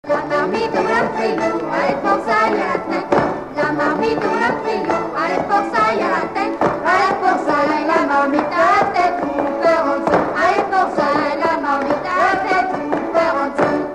Bois-de-Céné
Couplets à danser
branle : courante, maraîchine
Pièce musicale inédite